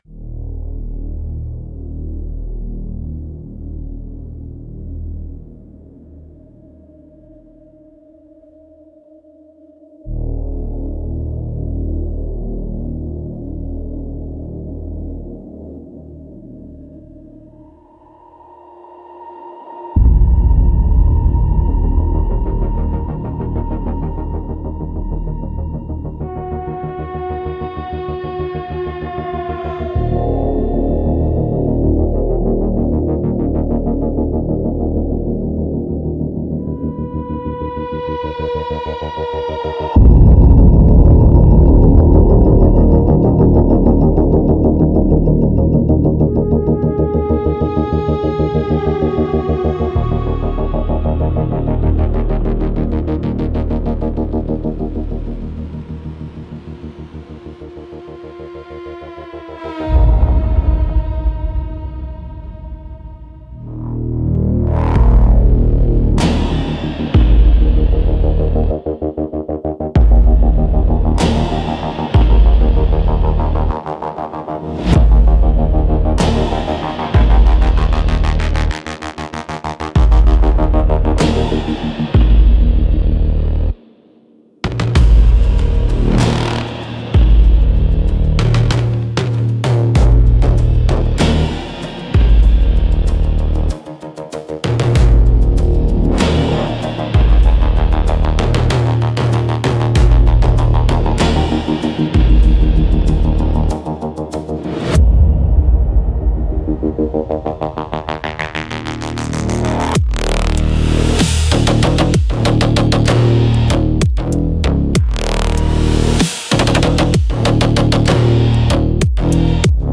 Instrumental - Caverns of Sub-Bass and Regret 3.37